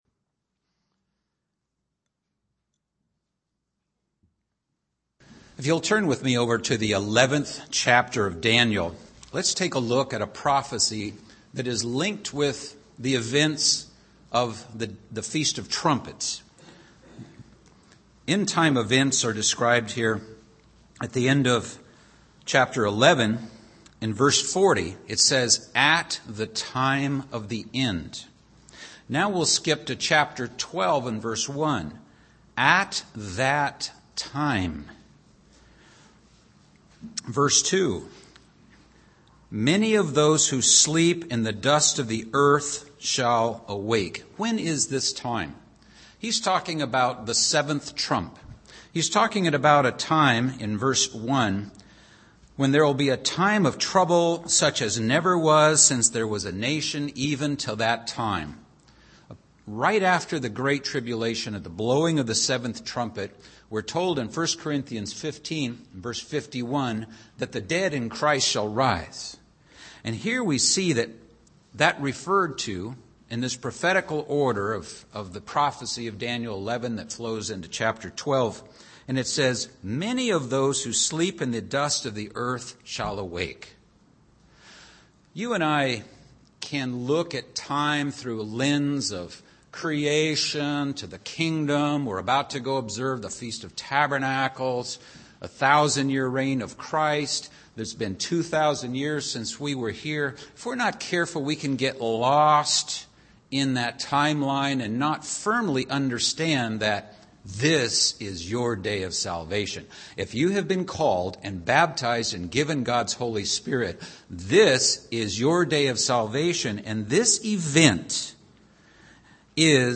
Yet, can one obtain salvation without also loving his neighbor as himself? This sermon examines the biblical injunction of loving both God and fellow man.